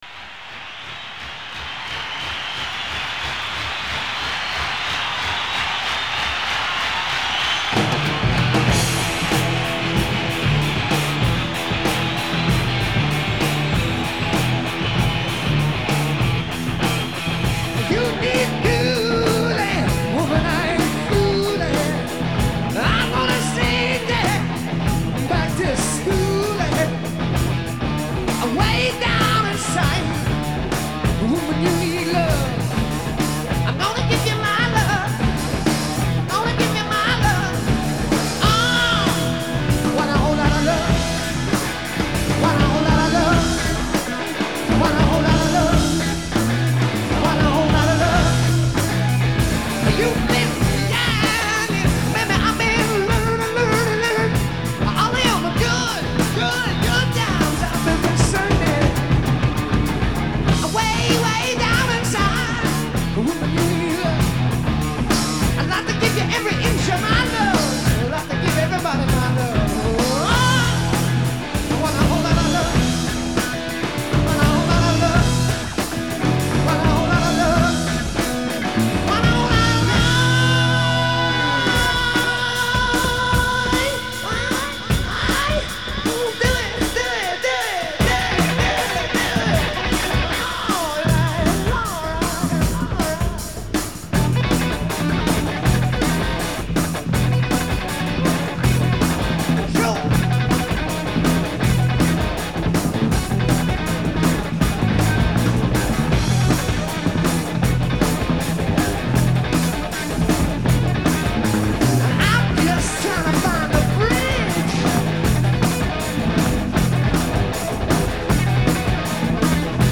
Guitare
Basse
Chant
Batterie